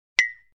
collectPack.mp3